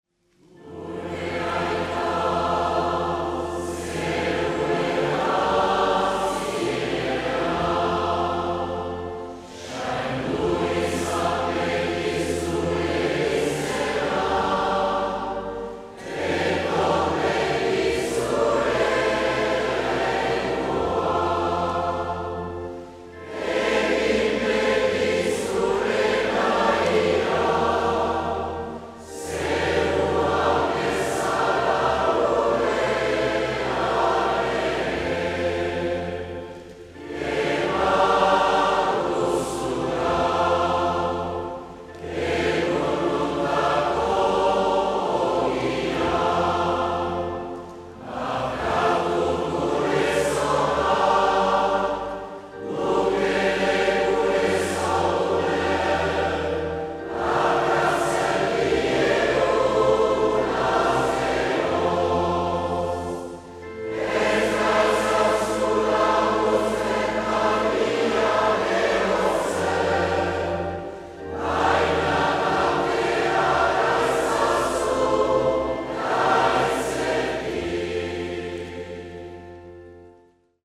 Gure Aita entzuteko (lau bozetan grabatua 2019. maitzaren 5an Hazparnen "Kanta Jaunari" elgarretaratzean) - Ecouter la nouvelle version du "Gure Aita" enregistrée à 4 voix mixtes.